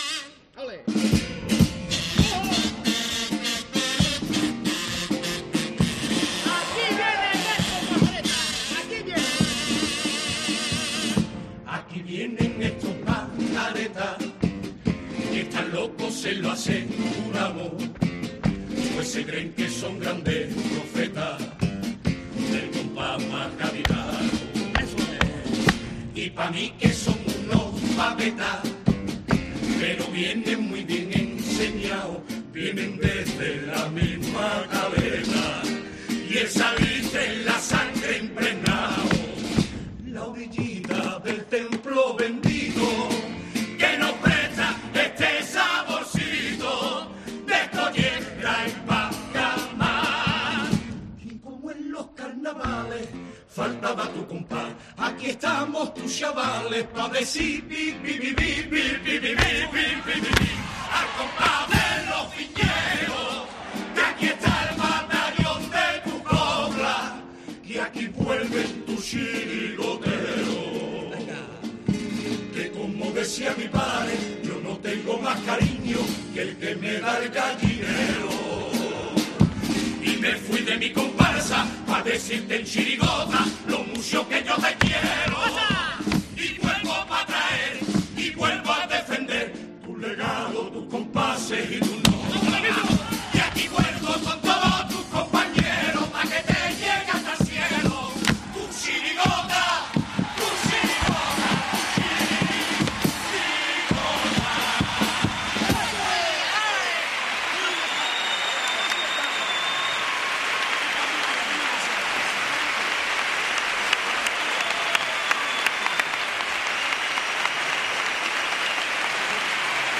Carnaval de Cádiz
Pasodoble La misión